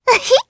Viska-Vox_Happy1_jp.wav